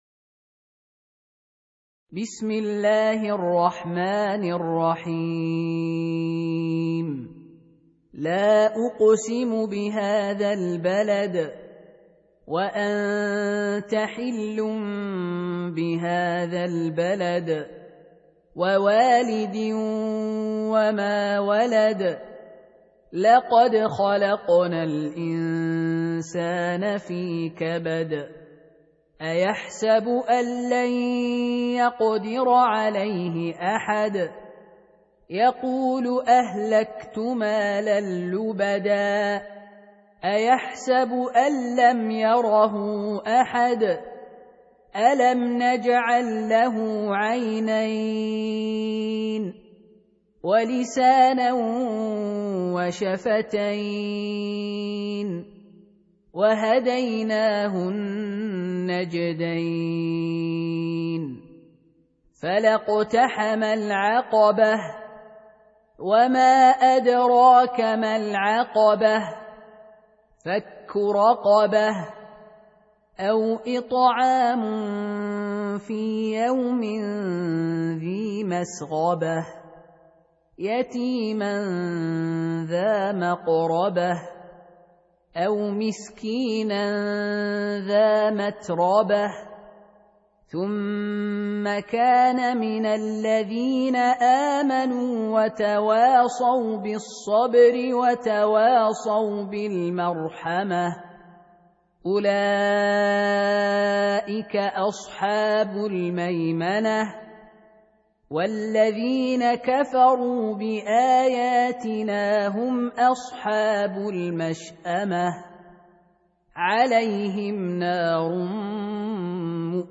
Surah Sequence تتابع السورة Download Surah حمّل السورة Reciting Murattalah Audio for 90. Surah Al-Balad سورة البلد N.B *Surah Includes Al-Basmalah Reciters Sequents تتابع التلاوات Reciters Repeats تكرار التلاوات